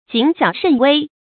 注音：ㄐㄧㄣˇ ㄒㄧㄠˇ ㄕㄣˋ ㄨㄟ
讀音讀法：
謹小慎微的讀法